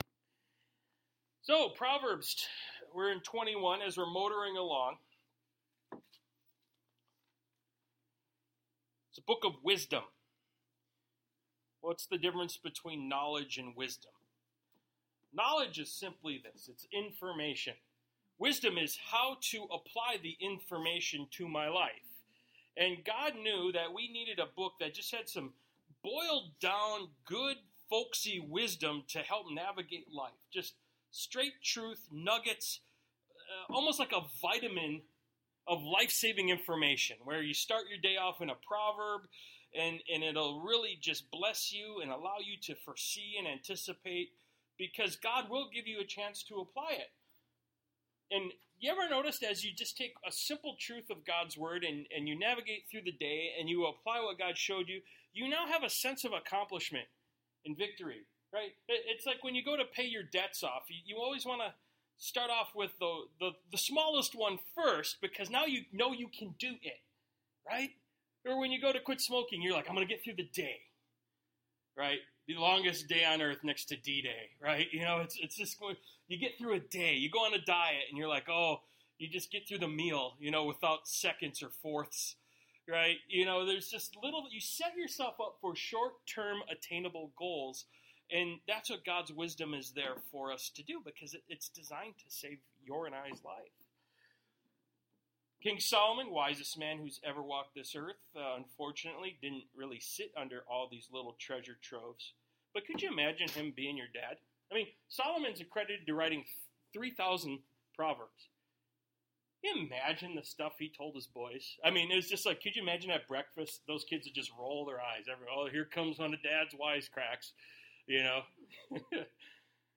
Service Type: Sunday Morning Bible Text: The king’s heart is in the hand of the Lord, Like the rivers of water; He turns it wherever He wishes.